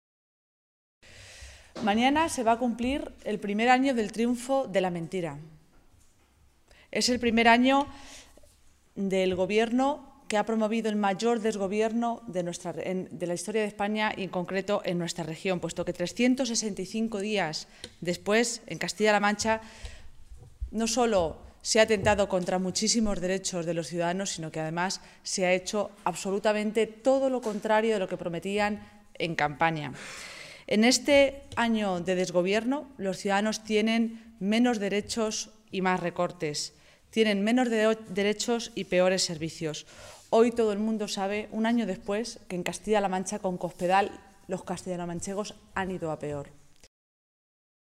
Cristina Maestre, portavoz de la Ejecutiva Regional del PSOE de Castilla-La Mancha
Cortes de audio de la rueda de prensa